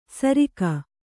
♪ sarika